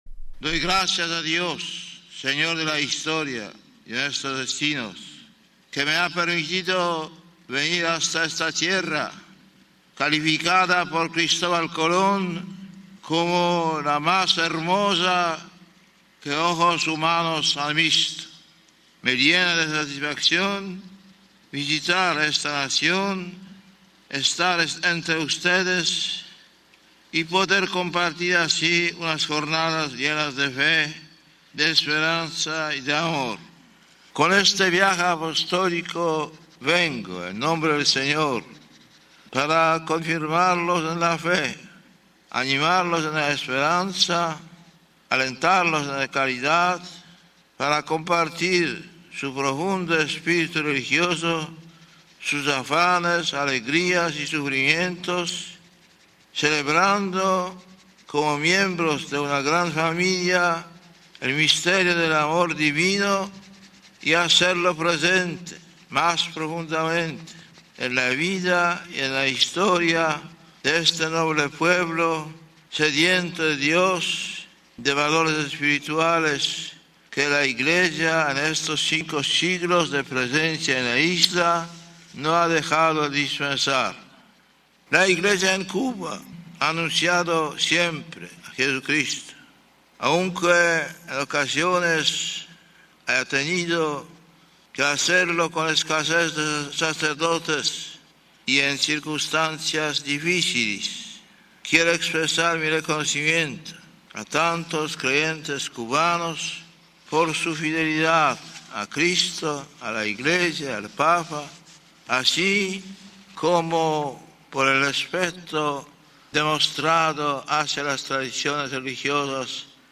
De nuestro archivo Sonoro traemos el entrañable recuerdo del Beato Juan Pablo II con el primer discurso de los 12 que pronunció en su memorable visita, apenas llegado a La Habana, el 21 de enero de 1998.
JUAN PABLO II, LA HABANA 21 ENERO 98